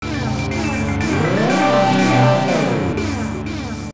this sound (loud warning) for transitioning between the two worlds.
LTTP_World_Warp.wav